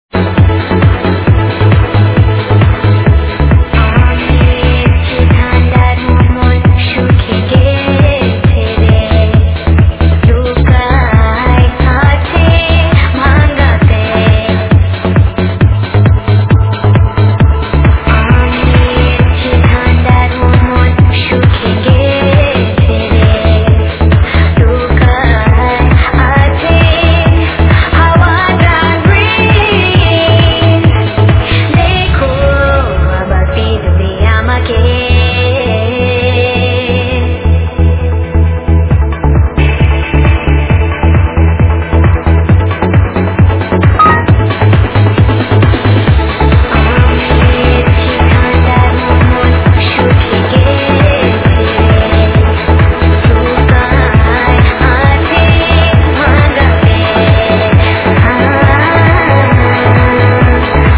its sounds like a club mix or something